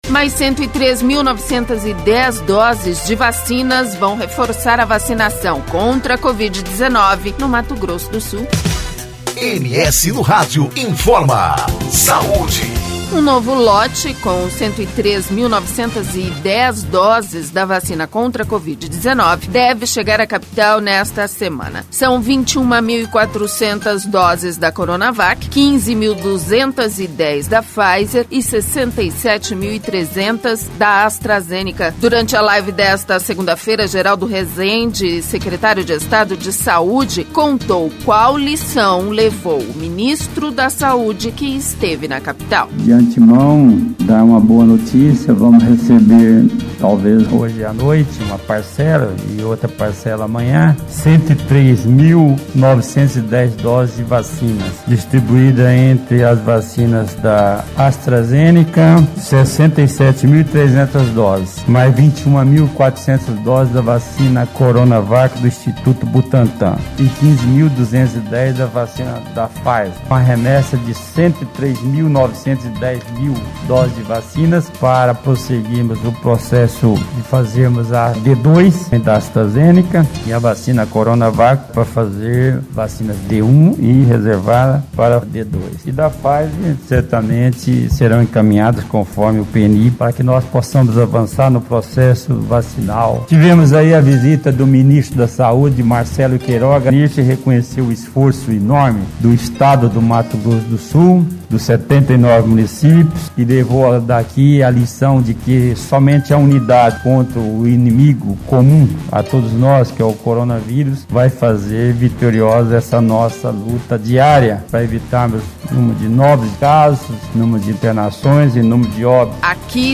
Durante a live desta segunda-feira, Geraldo Resende, secretário de estado de saúde, contou qual lição levou o Ministro da Saúde, que esteve na Capital.